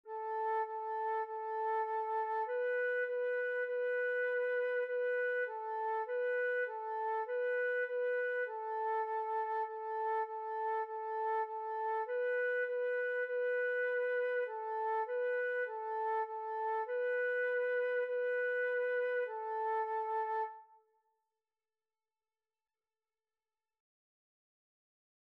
2/4 (View more 2/4 Music)
A5-B5
Instrument:
Flute  (View more Beginners Flute Music)
Classical (View more Classical Flute Music)